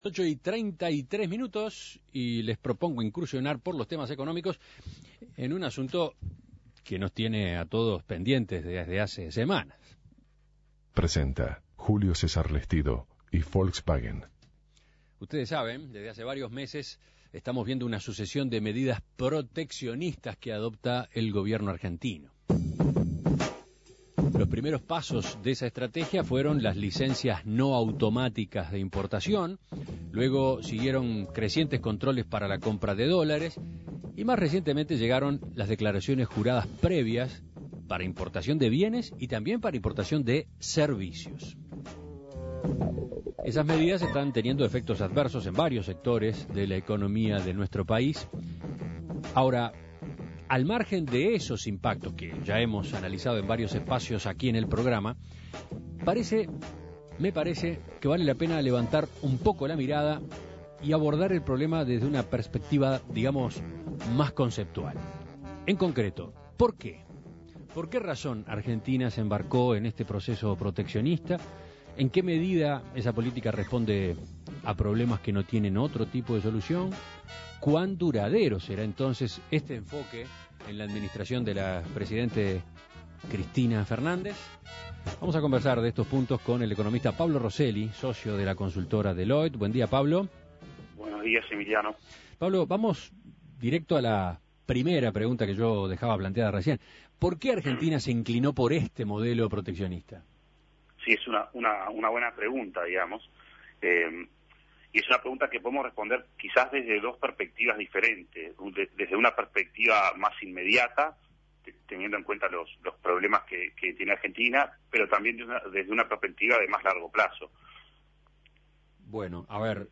Análisis Económico El proteccionismo argentino